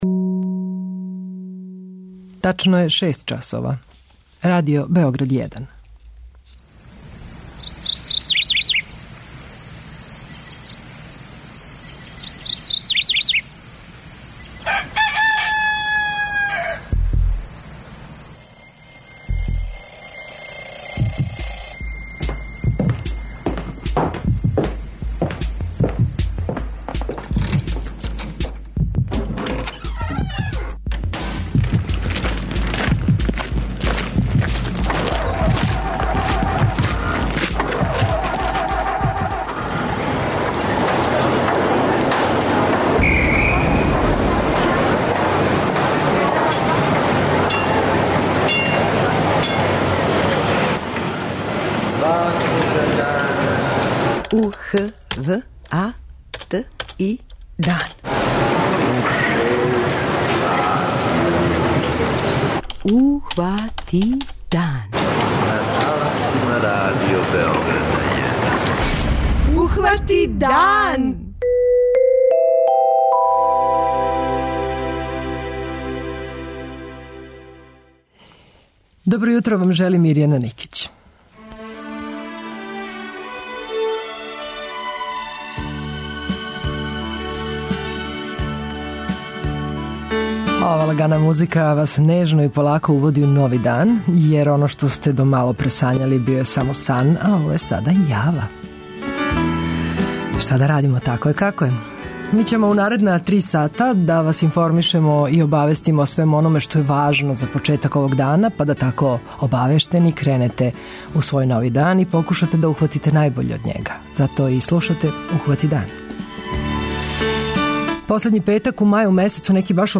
преузми : 85.97 MB Ухвати дан Autor: Група аутора Јутарњи програм Радио Београда 1!